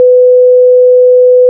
sinus.wav